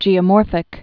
(jēə-môrfĭk)